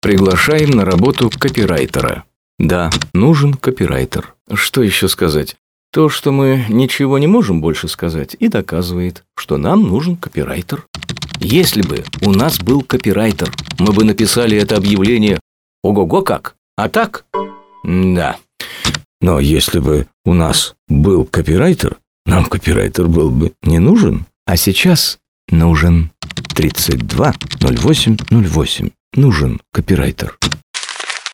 Вид рекламы: Радиореклама